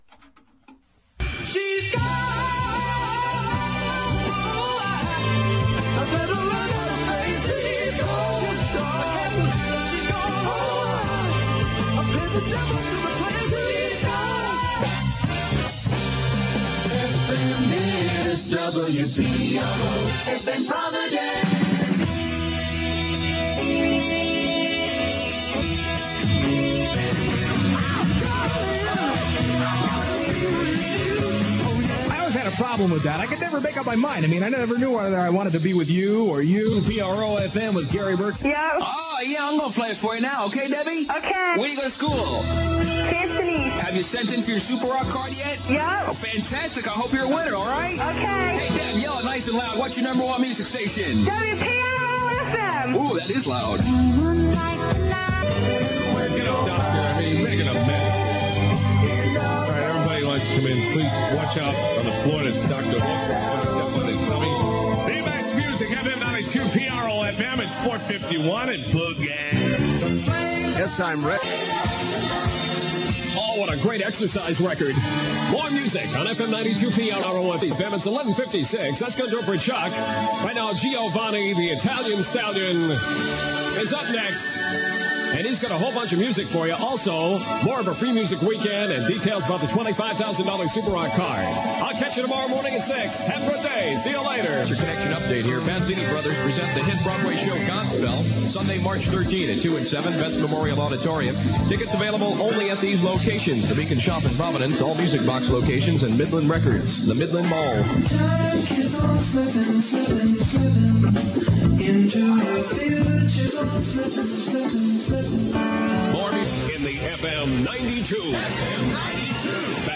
Composite Aircheck, Summer/Fall 1976
In Stereo.
It was loud, brashy Top-40 FM radio.....and we loved it!!
It sounds as crisp and clear (and LOUD!!) as you remember!!